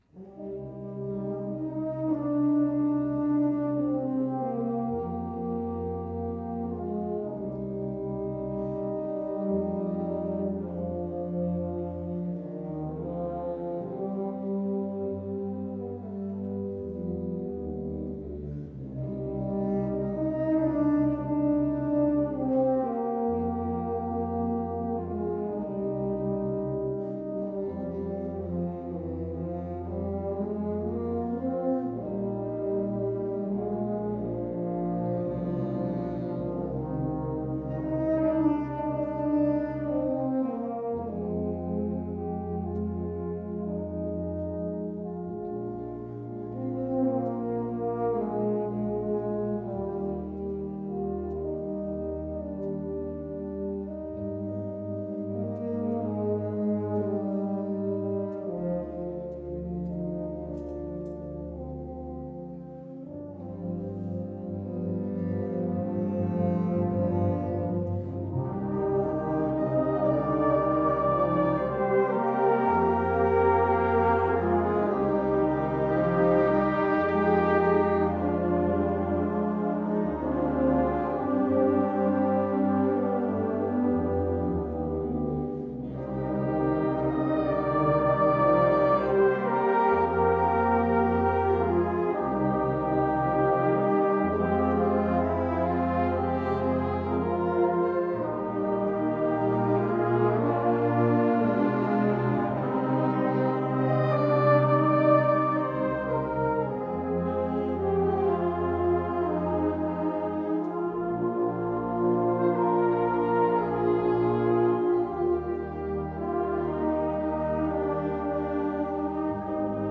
LBM Open podium (16 april 2023, Theater de Maaspoort Venlo)